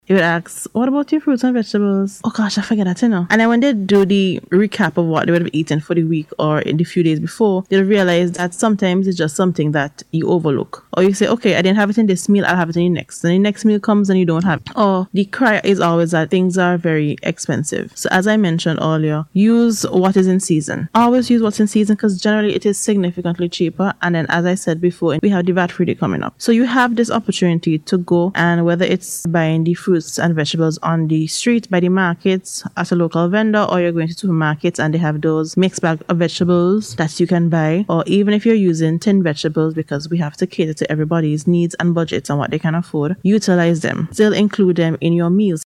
Speaking on radio recently